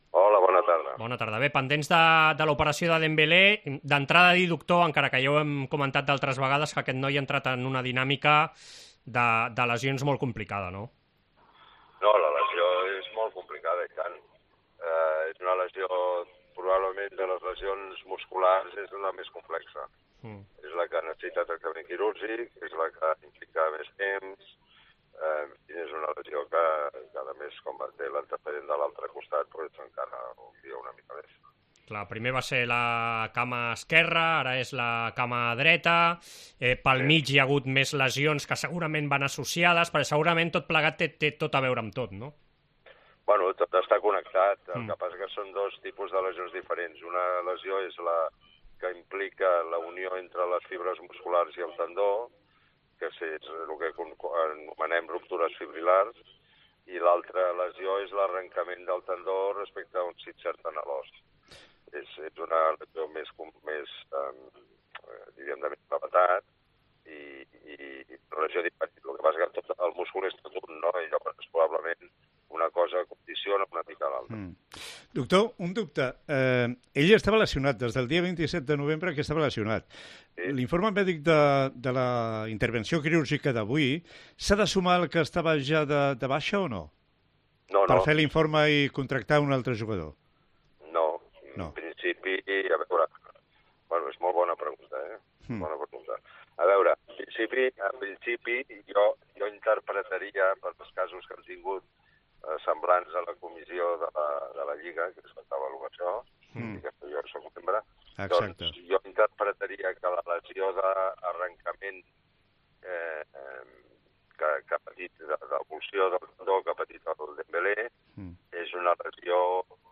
AUDIO: Entrevista amb el traumatòleg membre de la Comissió mèdica de la Lliga de futbol professional que valorarà si es tracta d'una baixa de llarga...